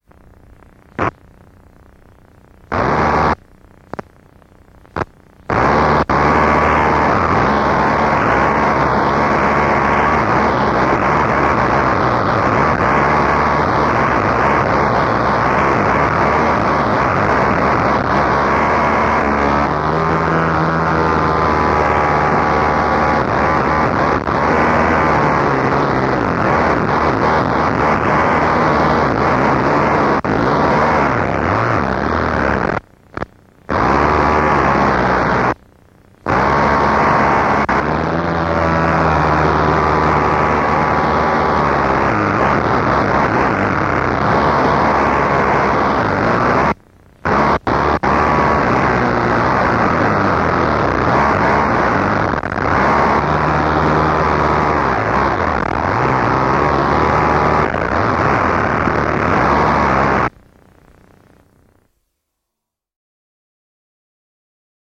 layered and sometimes delayed...
• Genre: Death Industrial / Power Electronics